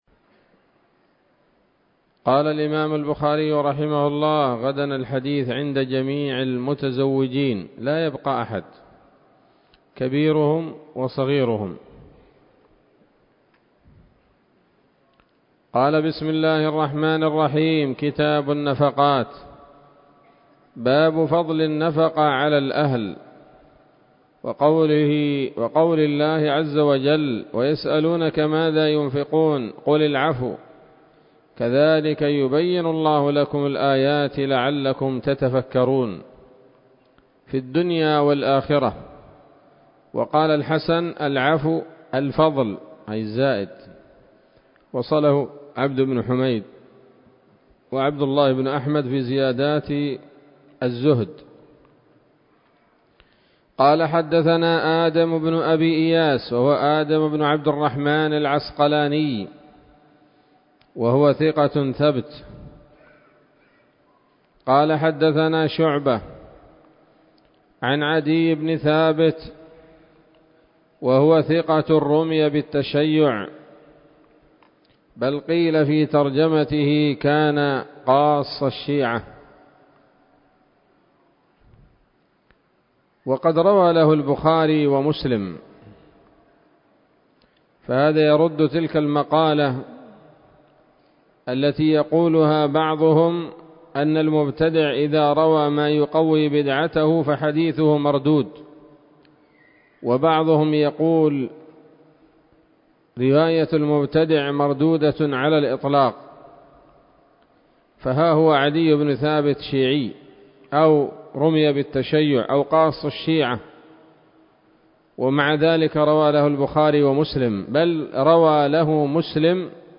الدرس الأول من كتاب النفقات من صحيح الإمام البخاري